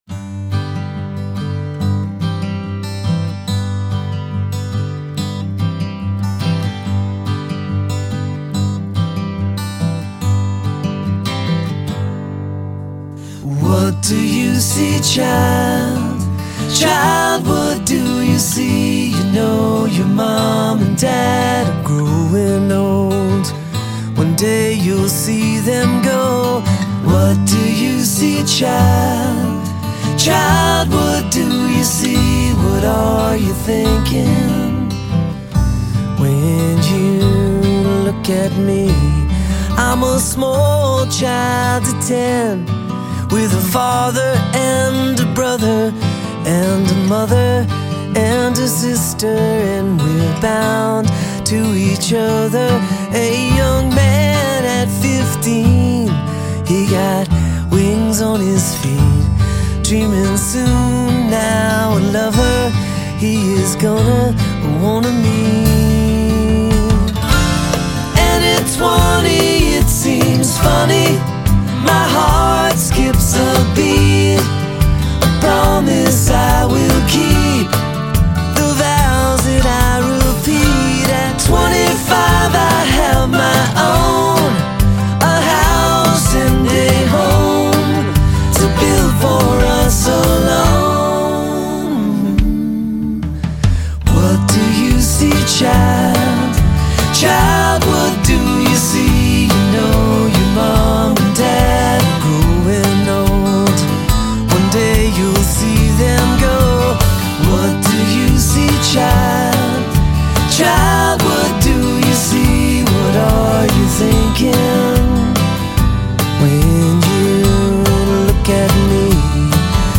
low-key acoustic